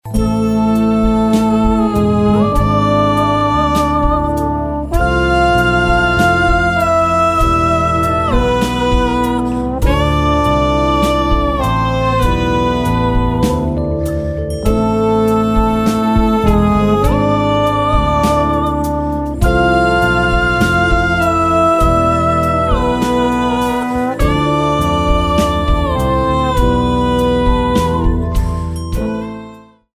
Genre: Alternative